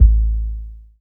MB Kick (18).WAV